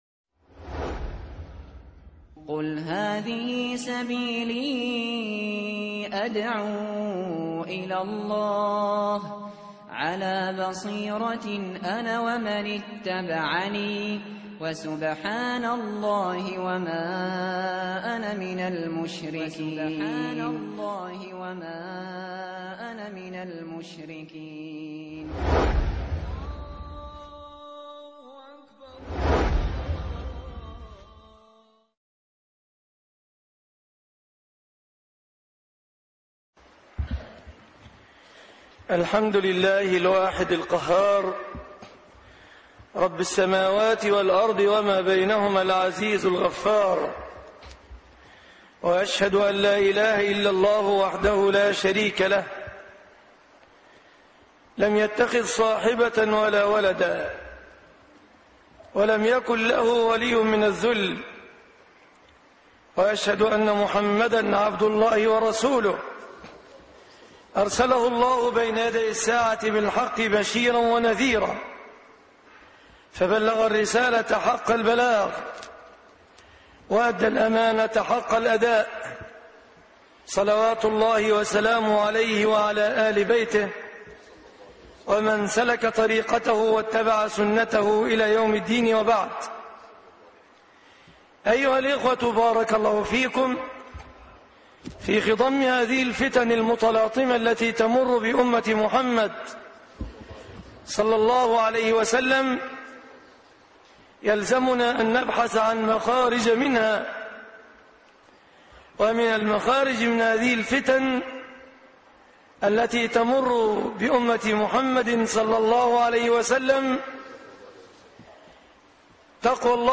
الوصايا الربانية- خطب الجمعة - فضيلة الشيخ مصطفى العدوي